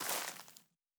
Undergrowth_Mono_01.wav